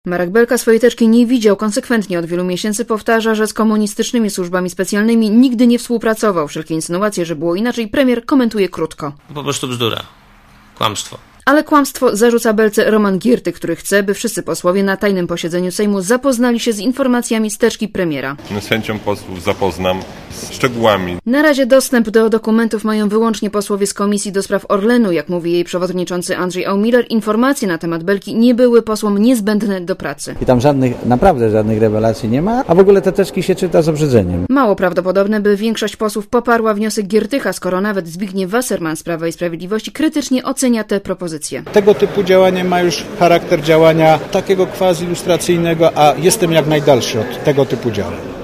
Relacja
reporterki Radia ZET